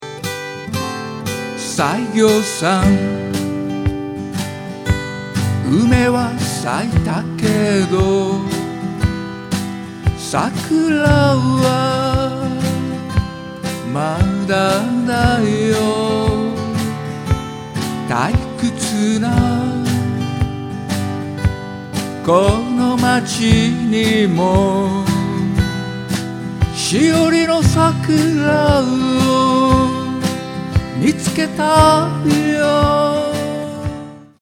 Percussion / Cajon / Cho.
Vo. / A.Guitar
A.Guitar / E.Guitar / Cho.